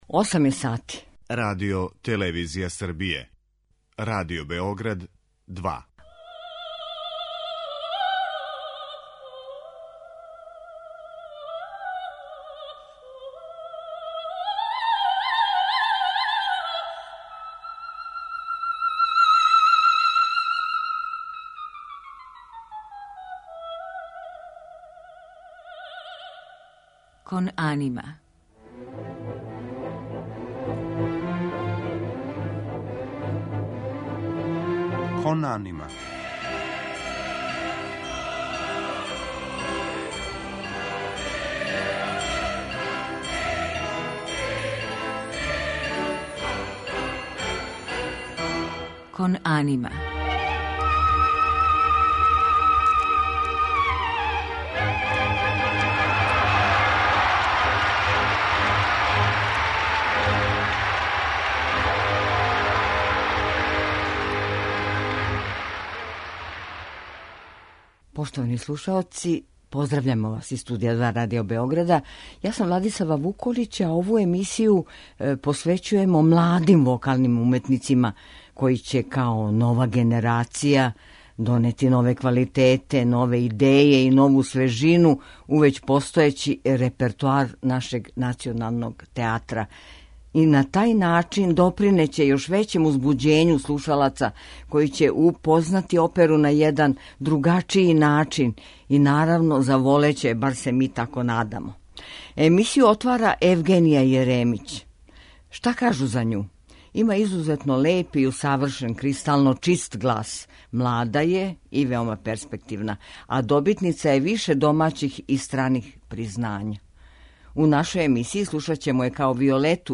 Познате арије у извођењу младих вокалних уметника.
У музичком делу биће емитовани фрагменти из опера Вердија, Чајковског, Пучинија, Вагнера и Росинија у њиховом извођењу.